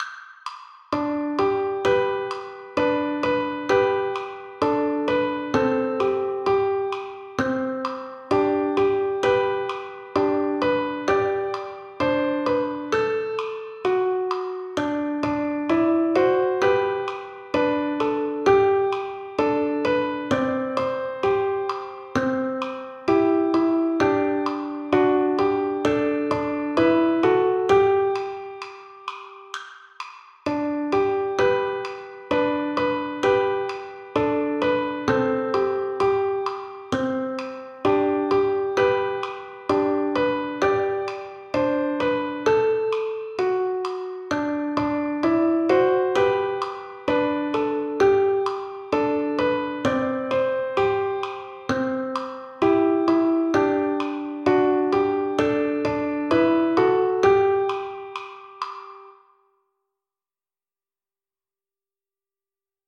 Canción tradicional de Estados Unidos.